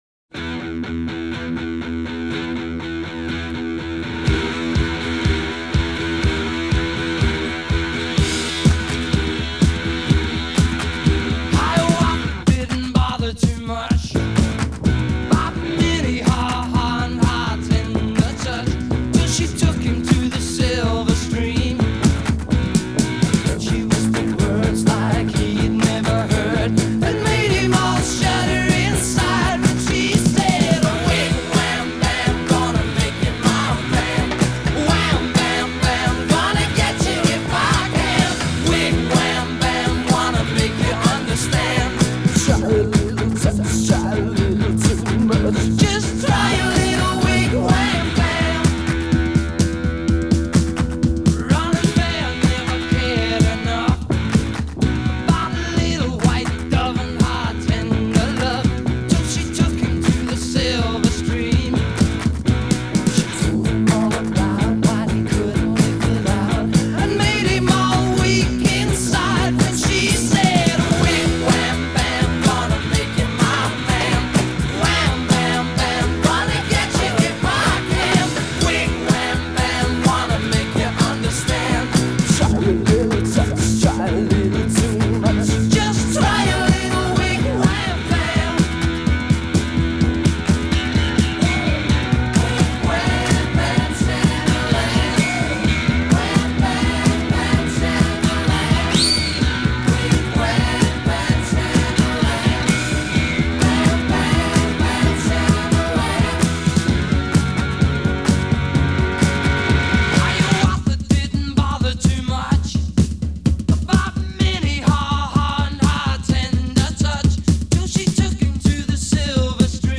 Genre: Glam Rock, Hard Rock